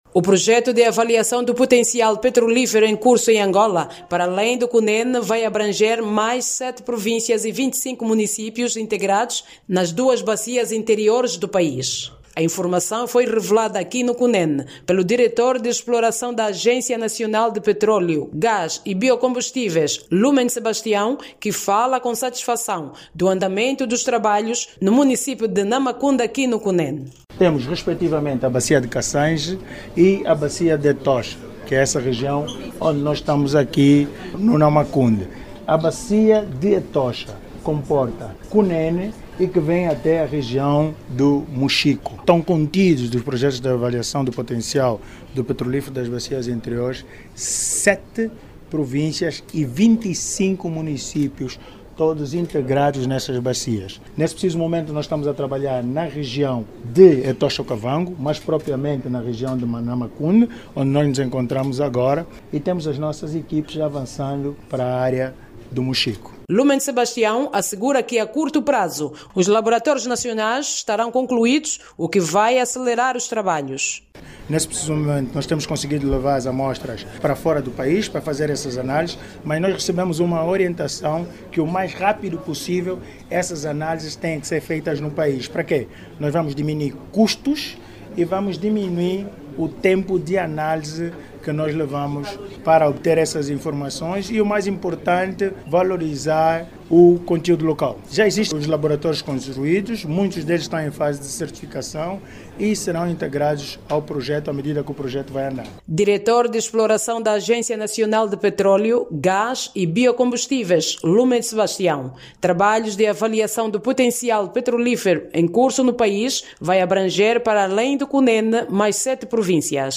No Cunene, as autoridades locais avançam que os trabalhos de prospecção já atingiram cerca de 60% de execução. Jornalista